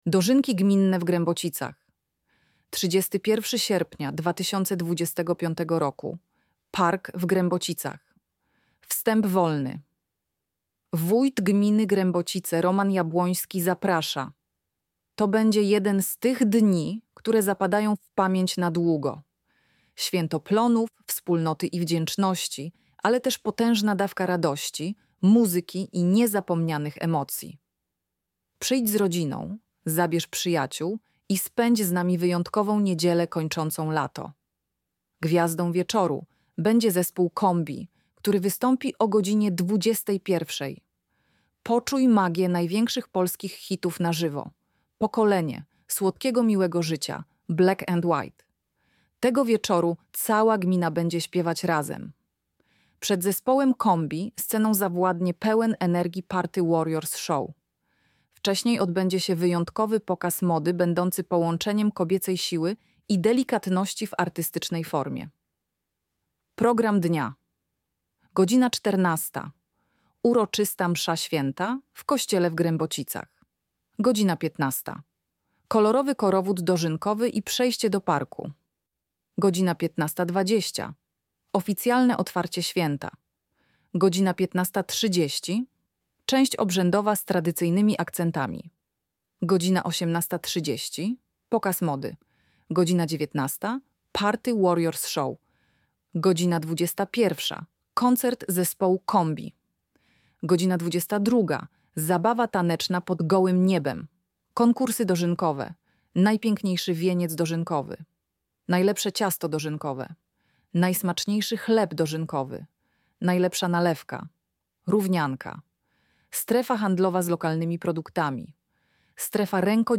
Lektor-Dozynki.mp3